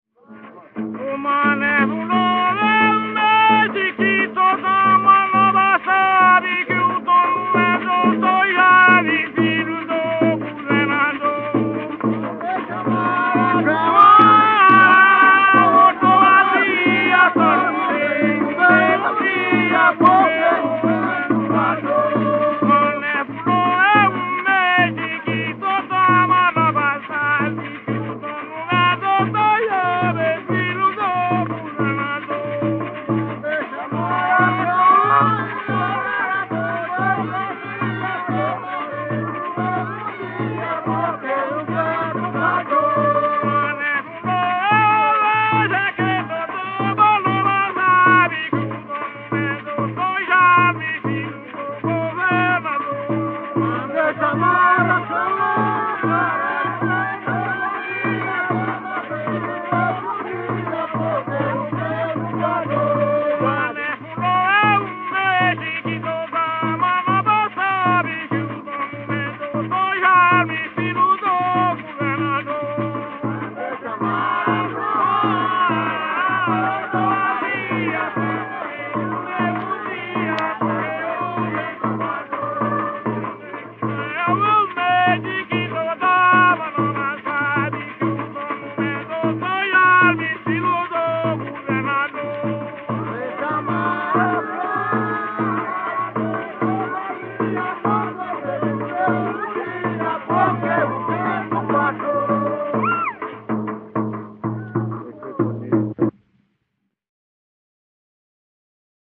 Coco embolada